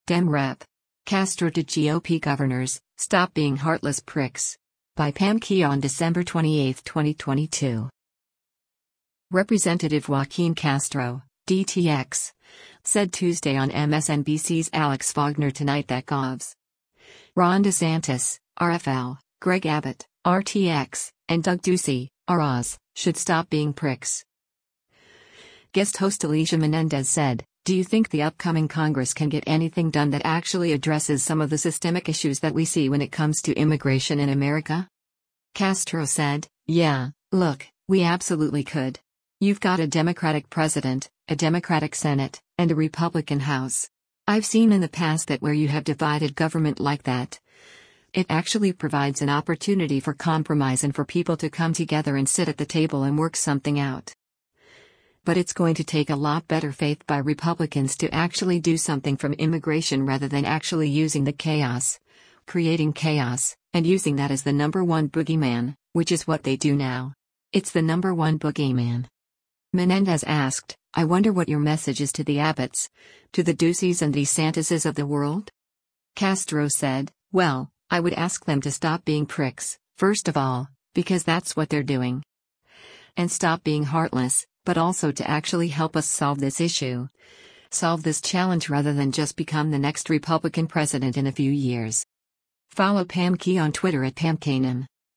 Representative Joaquin Castro (D-TX) said Tuesday on MSNBC’s “Alex Wagner Tonight” that Govs. Ron DeSantis (R-FL), Greg Abbott (R-TX) and Doug Ducey (R-AZ) should “stop being pricks.”